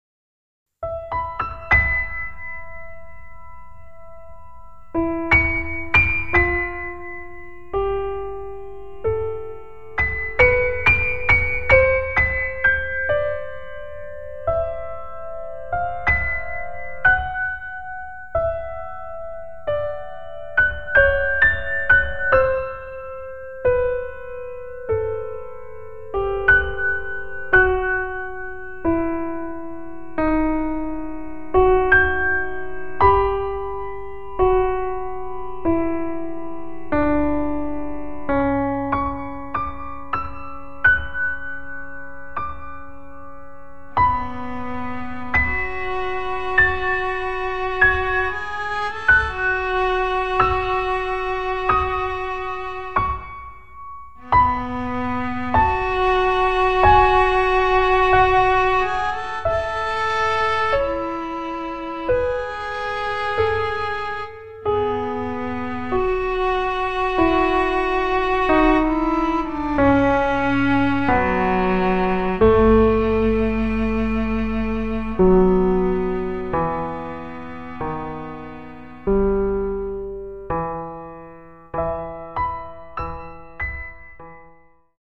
Filmisch
gebruikt in dansvoorstelling Pergola=Pistolet van LaMelis